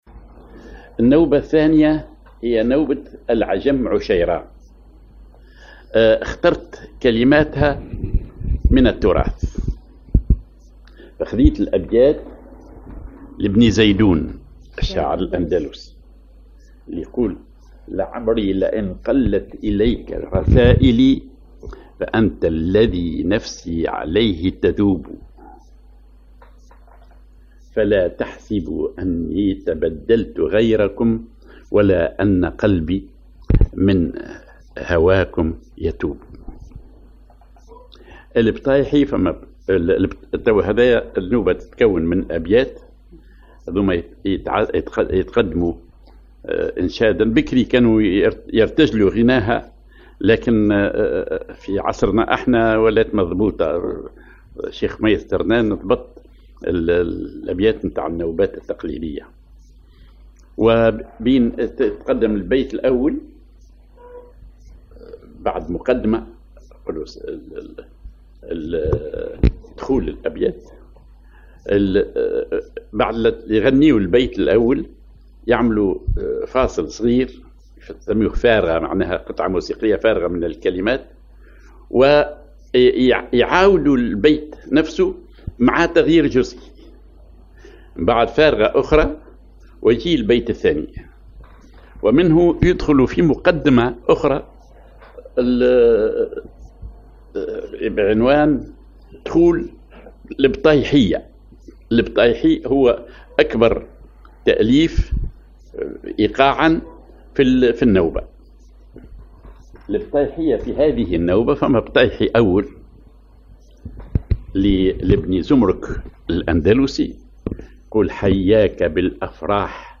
Maqam ar عجم عشيران
Rhythm ID سماعي ثقيل
genre سماعي